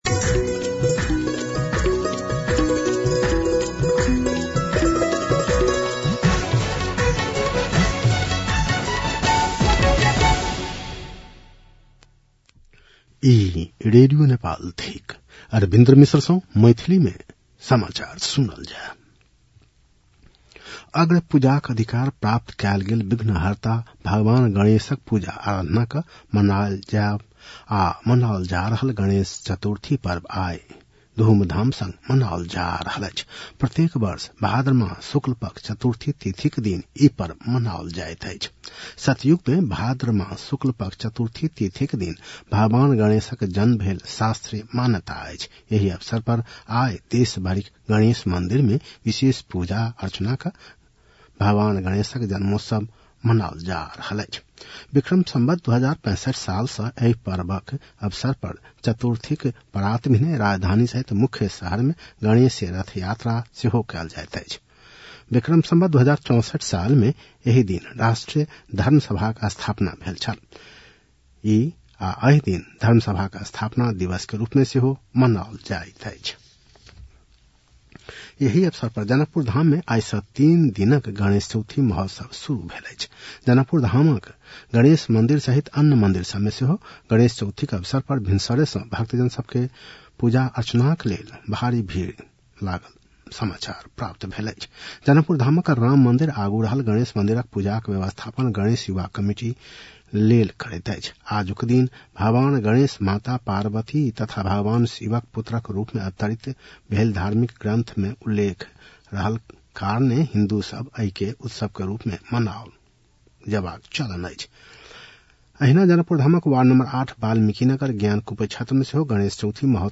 मैथिली भाषामा समाचार : ११ भदौ , २०८२